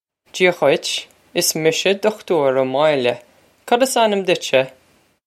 Jee-ah ghwitch. Iss misha Dukh-toor Oh Moyil-eh. Cod iss an-im ditch-sheh?
This is an approximate phonetic pronunciation of the phrase.